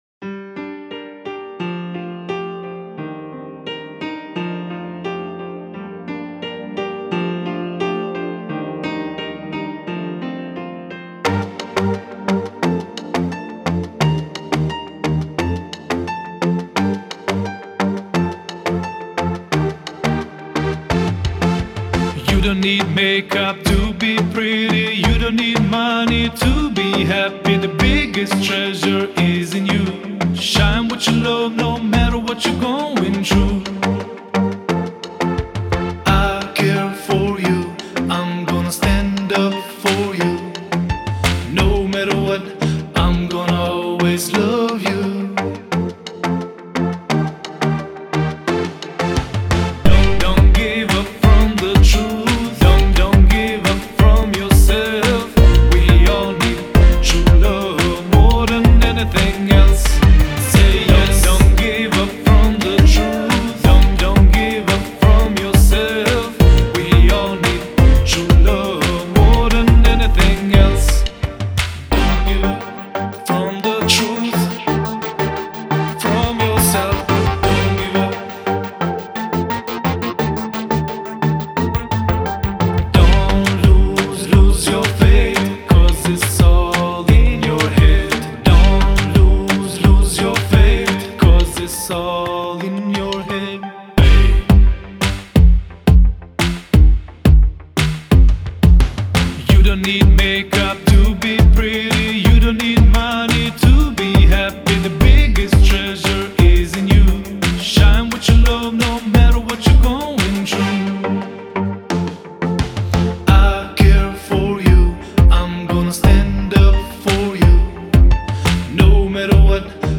Genre: Pop-R&B
BMP: 87.030